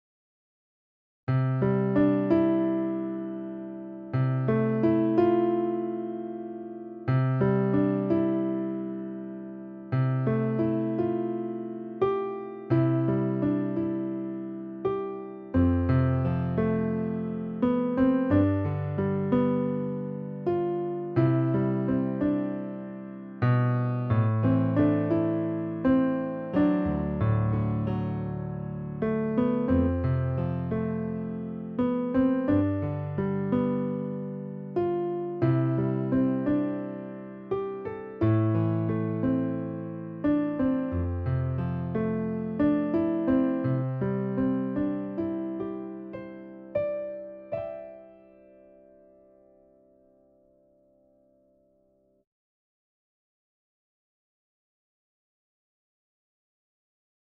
ピアノ曲